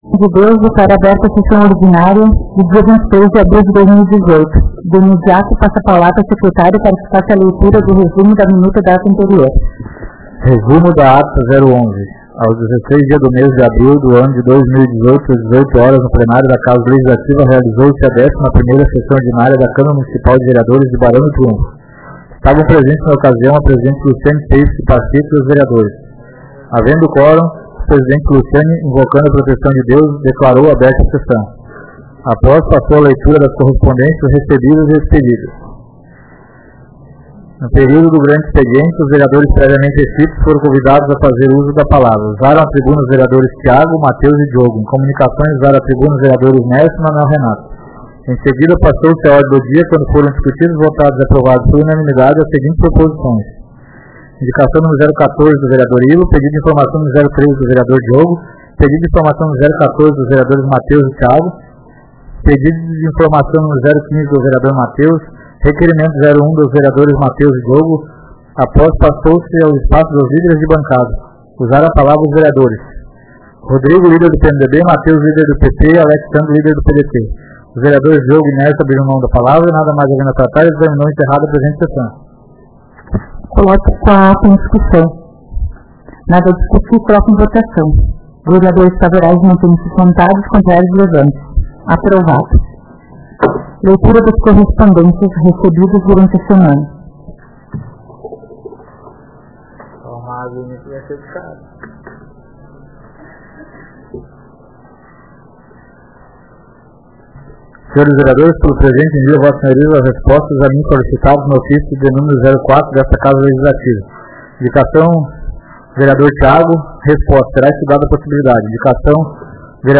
Áudio das Sessões